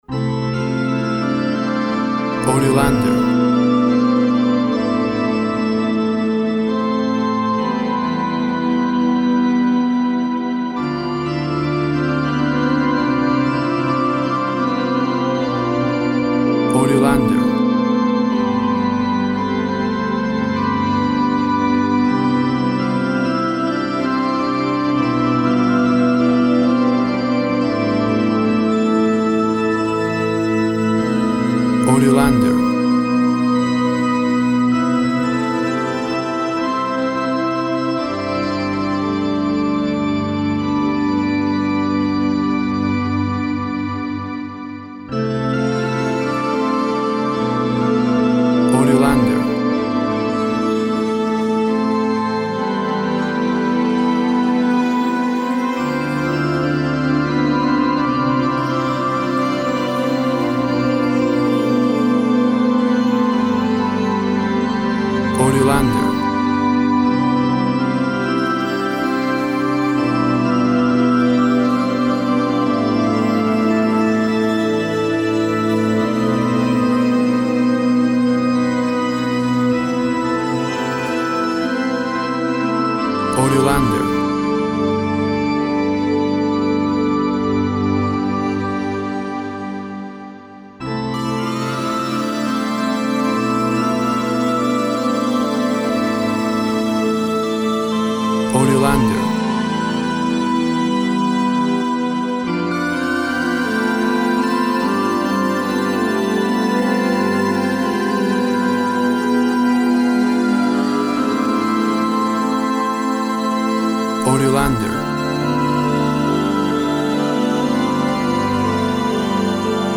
Tempo (BPM) 64/52